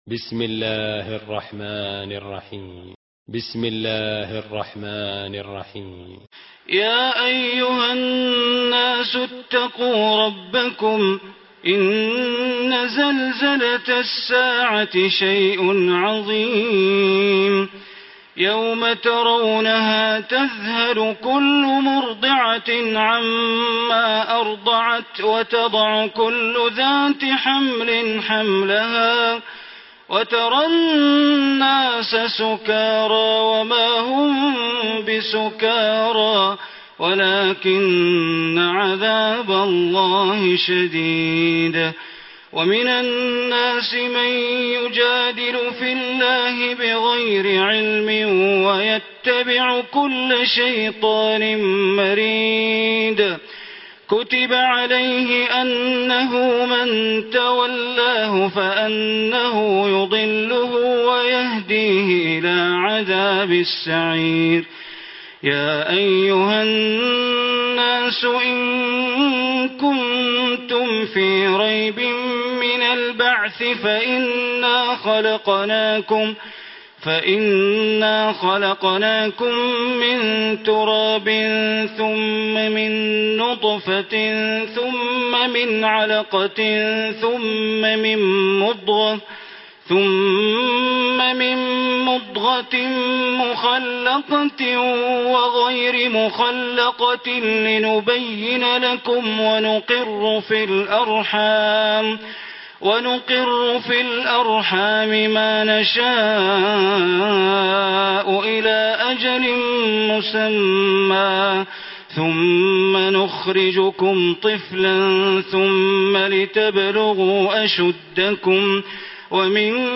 Surah Hajj Recitation by Sheikh Bandar Baleela